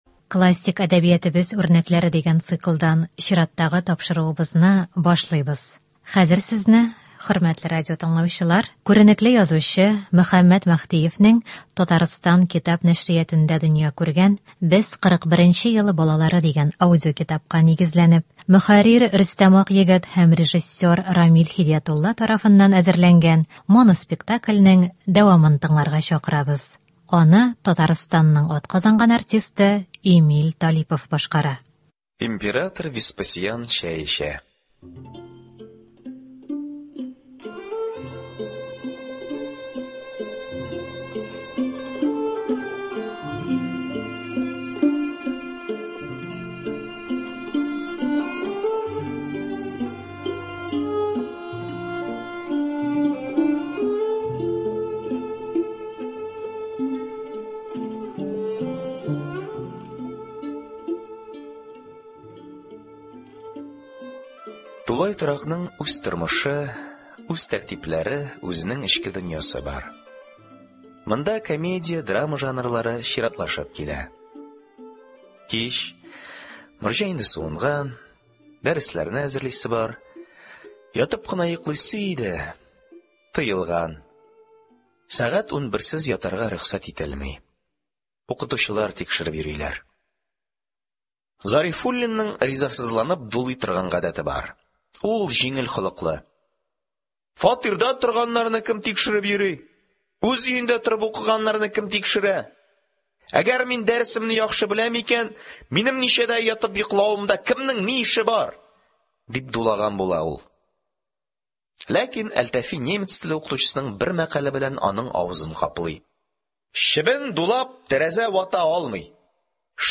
Моноспектакль.